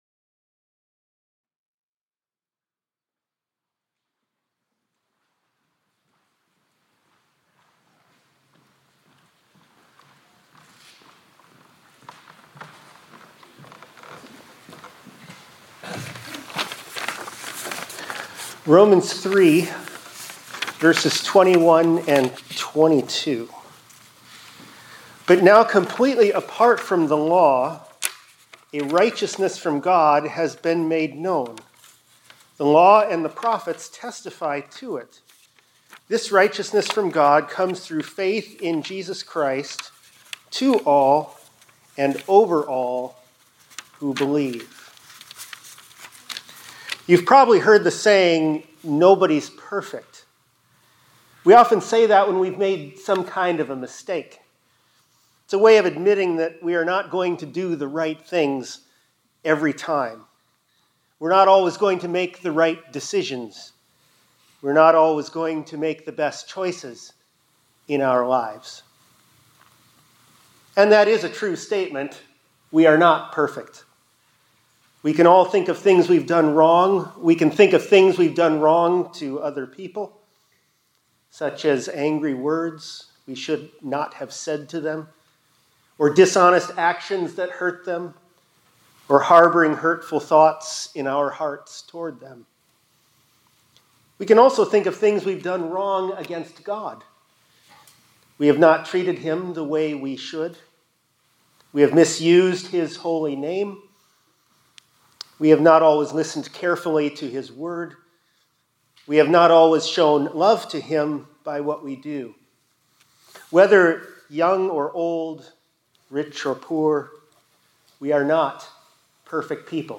2025-01-15 ILC Chapel — A Righteousness From God is Revealed